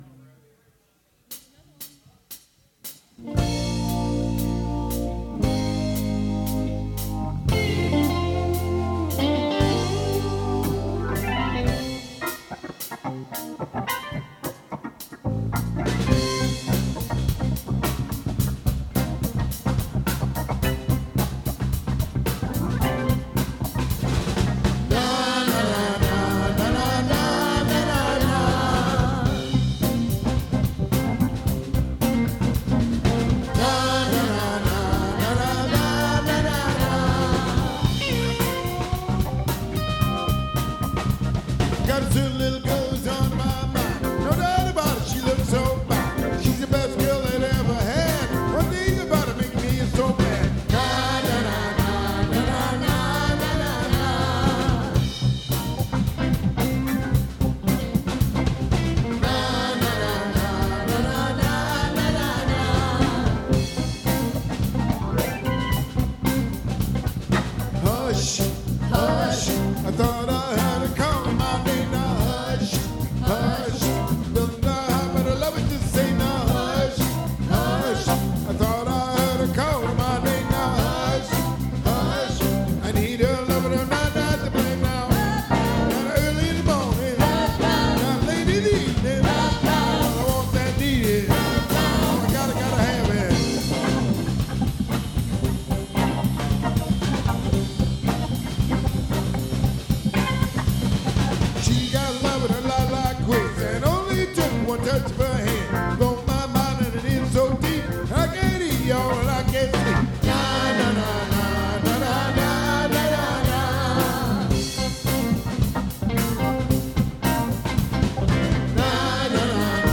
lead vocals, percussion, guitar
keyboards, vocals
saxophone
bass guitar
drums